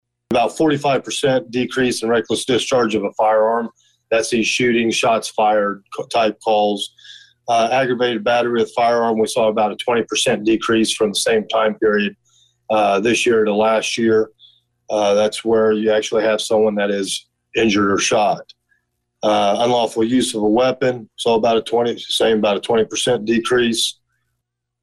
So says Danville Police Chief Christopher Yates who issued a report Tuesday evening to the Public Services Committee of the Danville City Council….